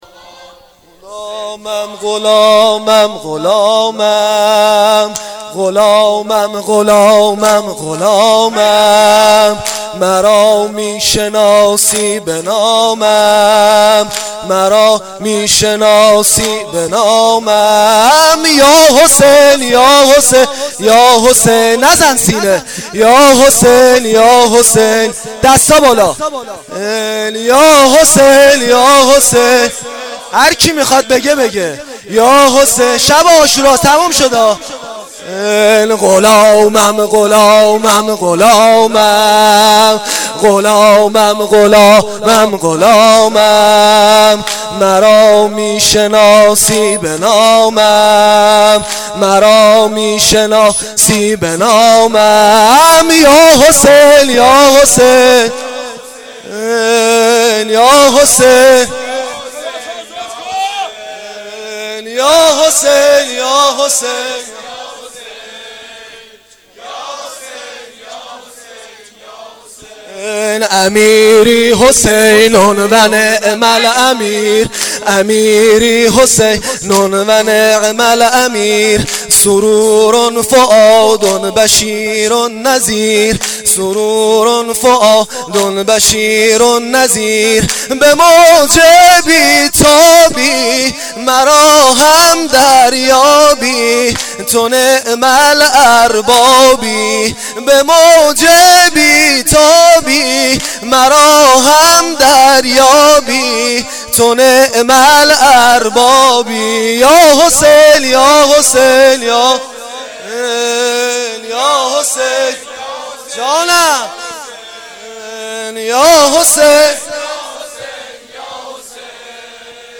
واحد شب عاشورا محرم1393